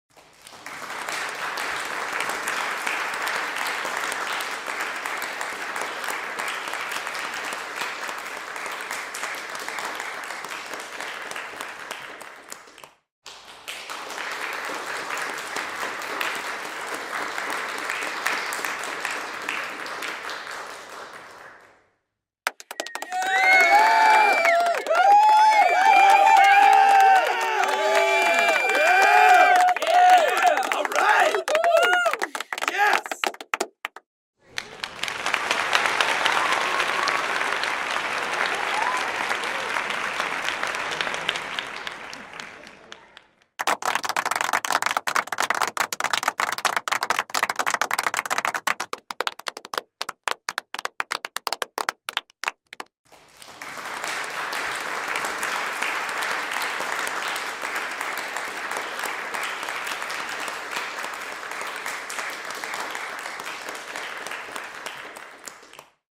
دانلود صدای دست زدن 1 از ساعد نیوز با لینک مستقیم و کیفیت بالا
جلوه های صوتی
برچسب: دانلود آهنگ های افکت صوتی انسان و موجودات زنده دانلود آلبوم صدای دست زدن و تشویق از افکت صوتی انسان و موجودات زنده